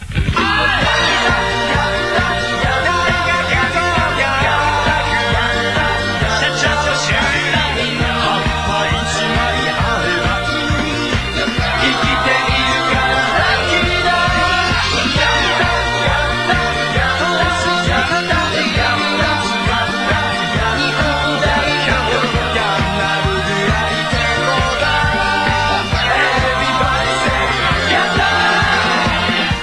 ambient3.wav